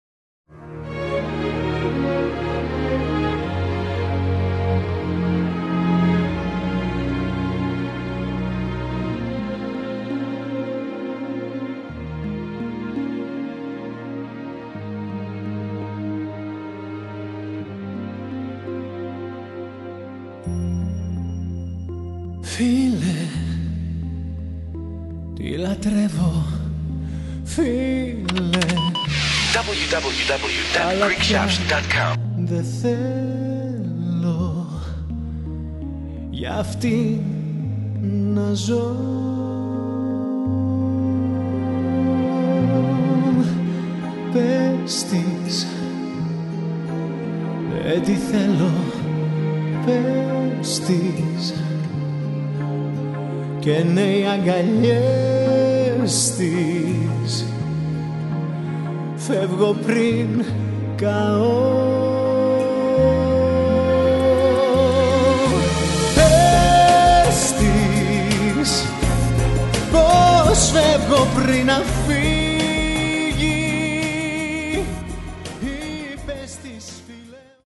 17 modern Elafra 'love songs' you're sure to enjoy